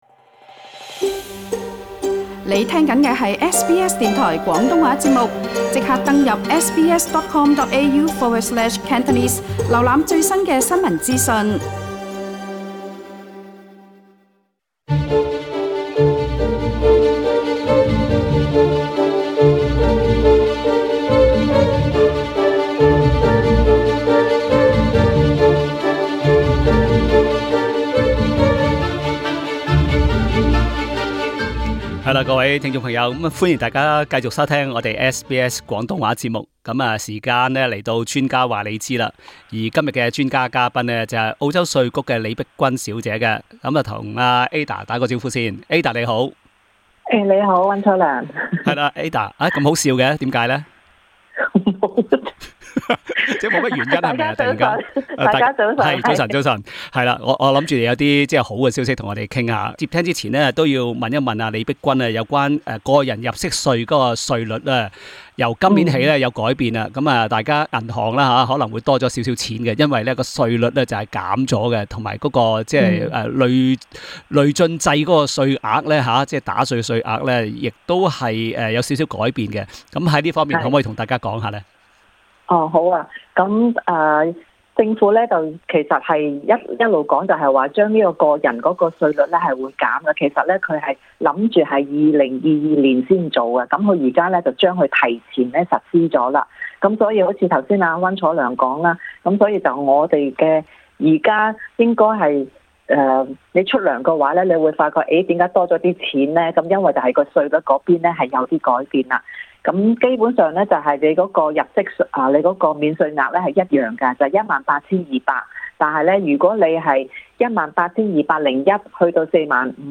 在接聽聽眾來電詢問之前，會和大家解釋聯邦政府把原定於兩年後的減受個人入息稅措施，提前於本財政年起執行。